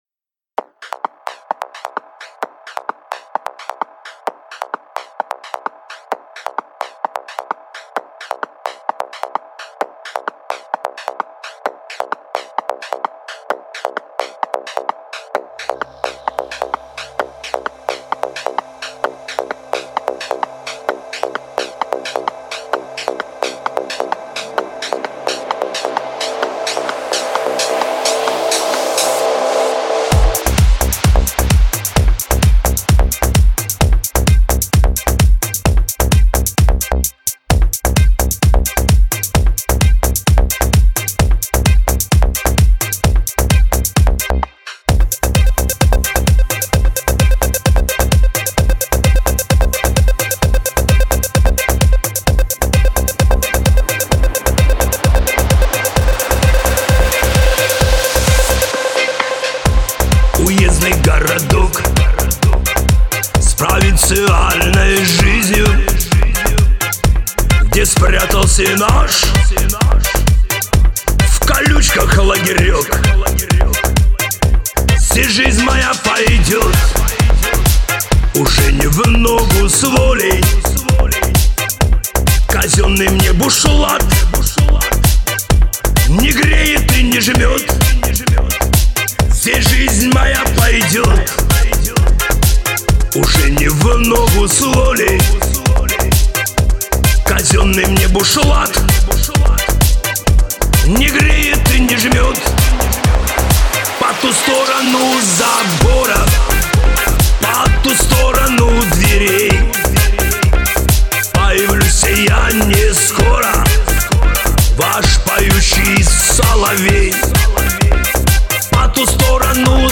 На мобилку » Mp3 » Шансон